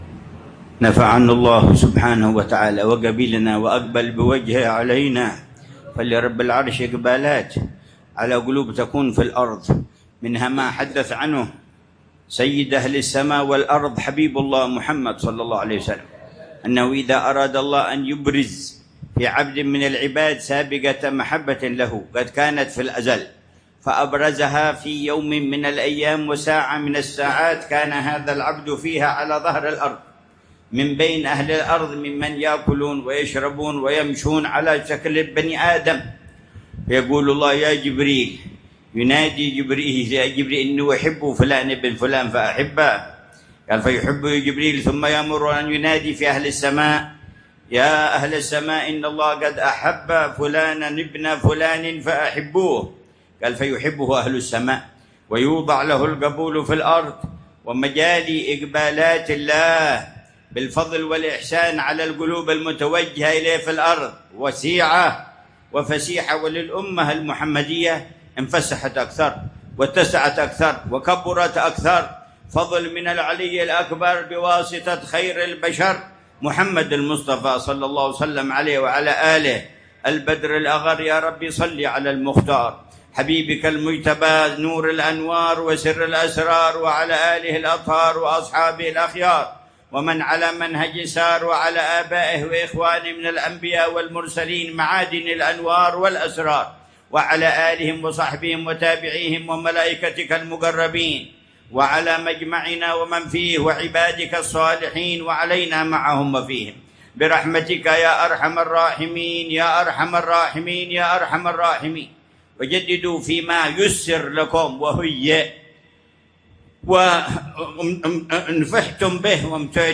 مذاكرة في المولد السنوي في روضة الحبيب سالم بن حفيظ في مشطة 1447هـ
مذاكرة الحبيب عمر بن محمد بن حفيظ في المولد السنوي في روضة العلامة الحبيب سالم بن حفيظ ابن الشيخ أبي بكر بن سالم في مشطة، حضرموت عصر الجمعة 20 ربيع الأول 1447 هـ